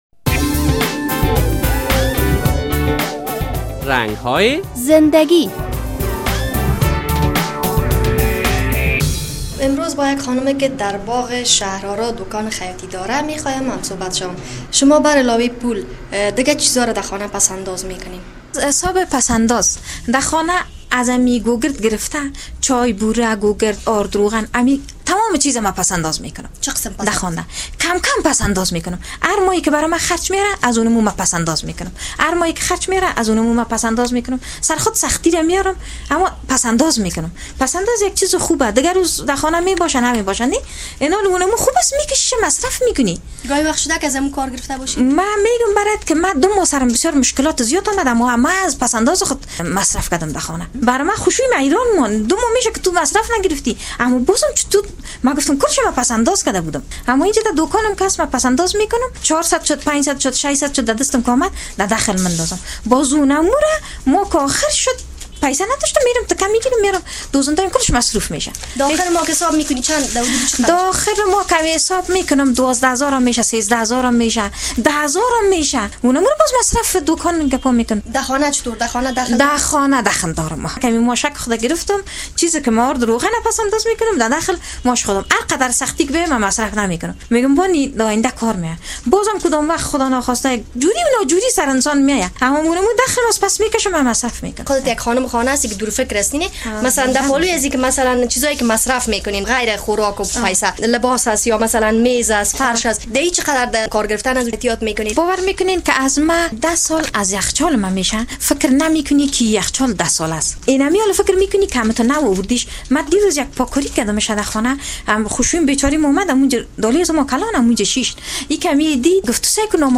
در این برنامهء رنگ های زندگی با یک خانم تجارت پیشه صحبت شده است. وی می گوید که پس انداز بسیار کار خوب است، من همیشه در خانه از کوچک ترین تا بزرگ ترین چیز خانه پس انداز می کنم.